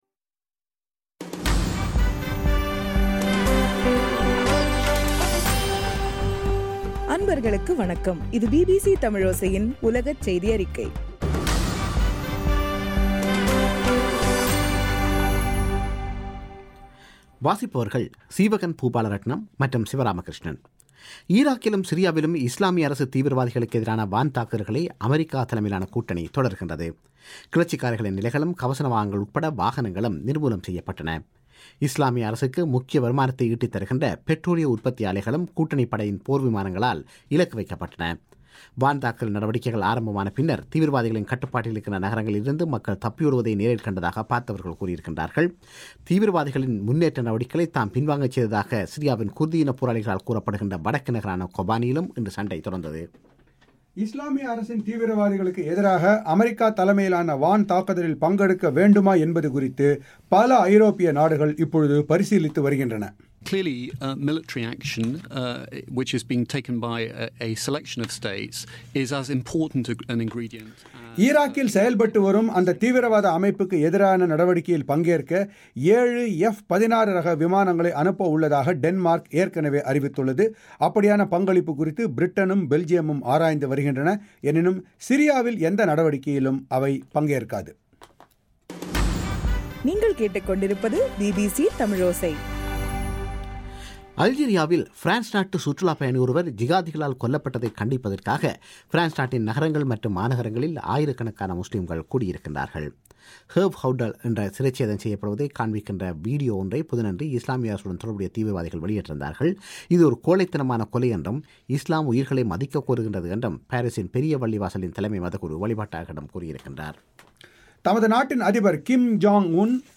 செப்டம்பர் 26 - பிபிசி உலகச் செய்திகள்